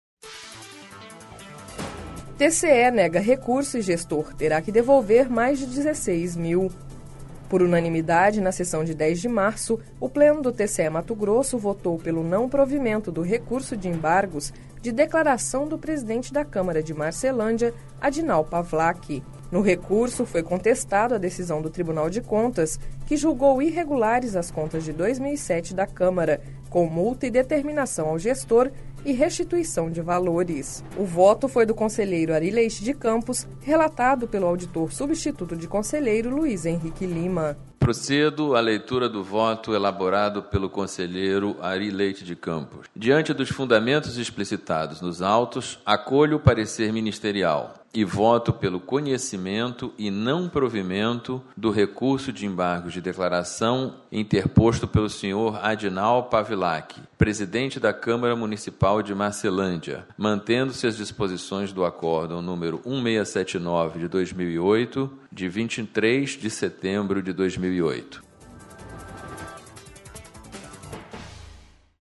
Sonora: Luiz Henrique Lima - auditor substituto de conselheiro do TCE-MT